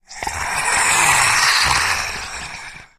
zombie_attack_3.ogg